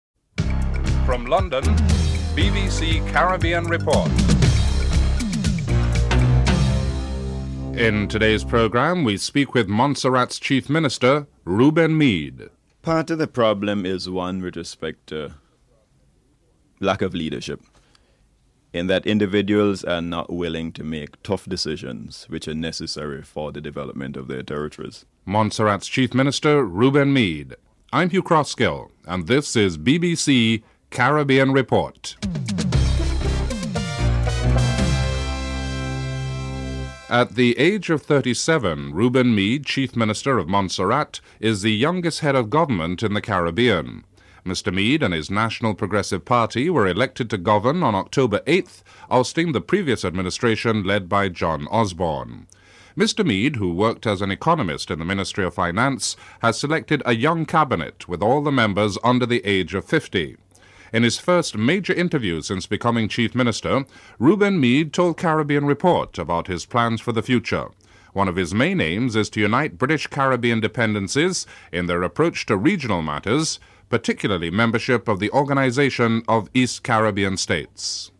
2. Interview with Reuben Meade, Chief Minister of Montserrat (00:36-14:44)